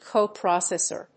発音記号
• / ˈkoˈprɑsɛsɝ(米国英語)
• / ˈkəʊˈprɑ:sesɜ:(英国英語)